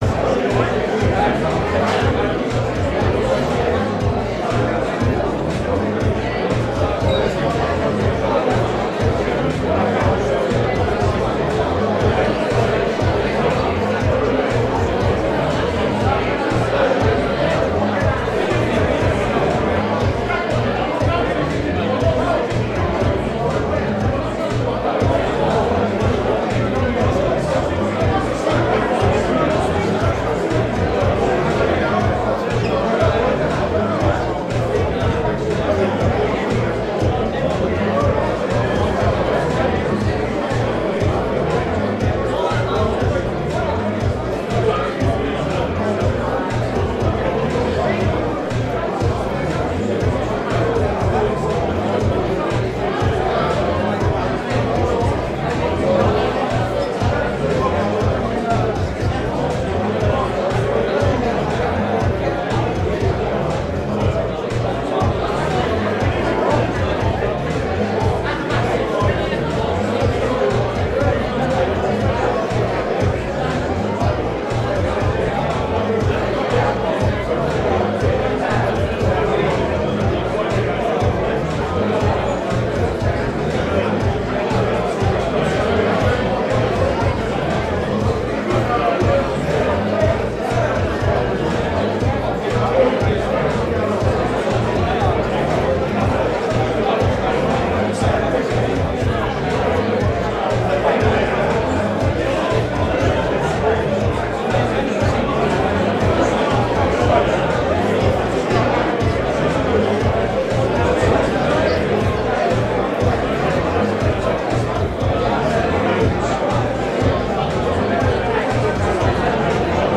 ambience_bar_large_busy_with_dance_music_in_background
Category: Sound FX   Right: Personal